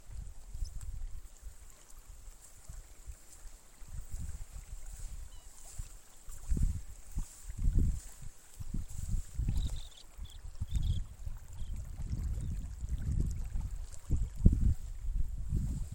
Playerito Unicolor (Calidris bairdii)
Nombre en inglés: Baird´s Sandpiper
Localidad o área protegida: Santa María
Condición: Silvestre
Certeza: Fotografiada, Vocalización Grabada